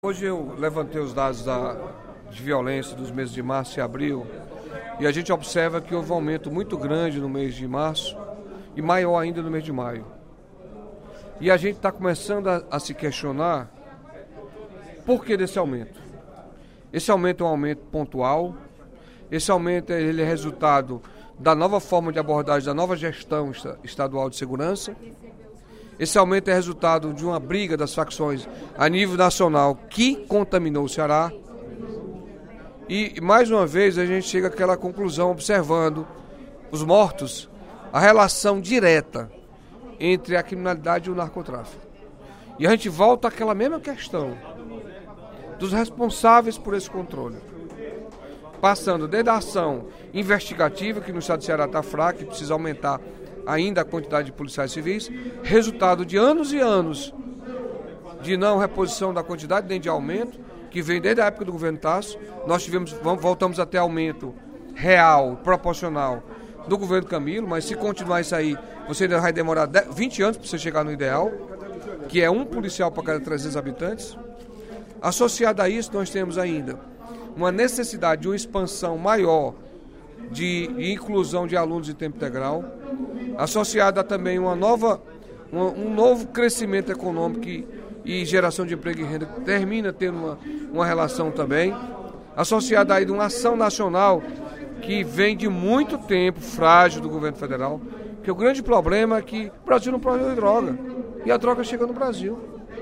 O deputado Carlos Felipe (PCdoB) questionou, durante o primeiro expediente da sessão plenária da Assembleia Legislativa desta terça-feira (13/06), o crescimento dos índices de violência no Ceará nos últimos dois meses.